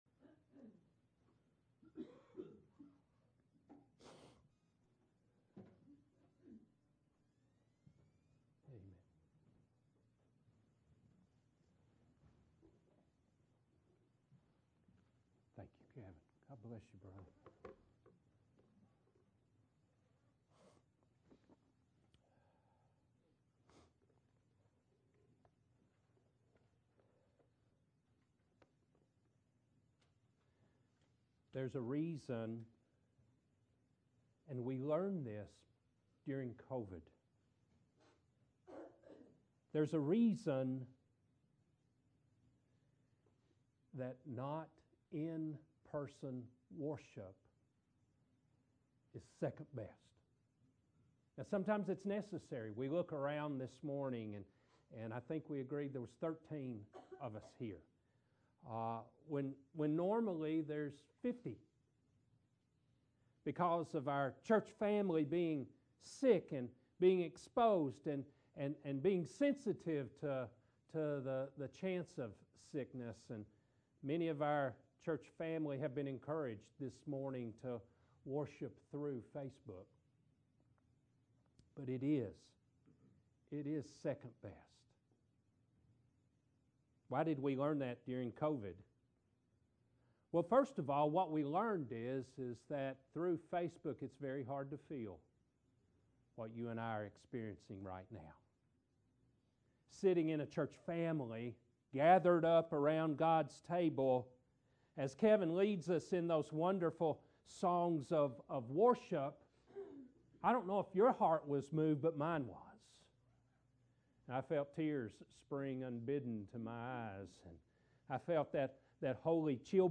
Matthew 2:11 Service Type: Morning Worship « Spiritual Disciplines